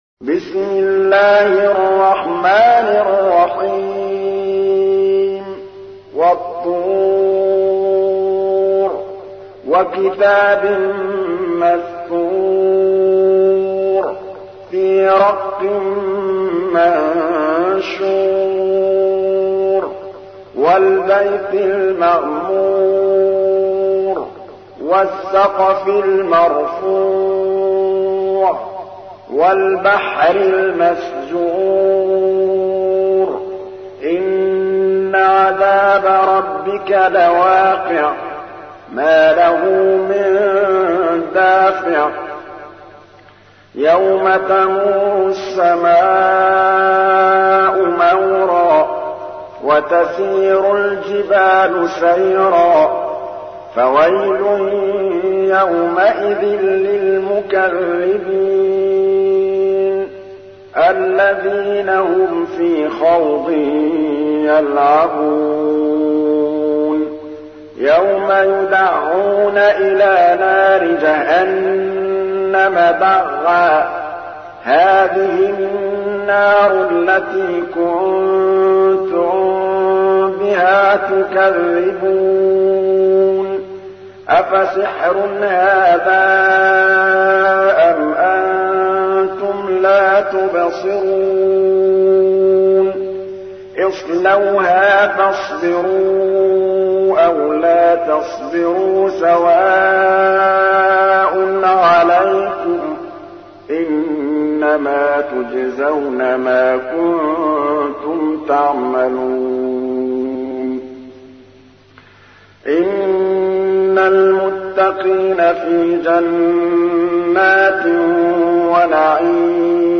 تحميل : 52. سورة الطور / القارئ محمود الطبلاوي / القرآن الكريم / موقع يا حسين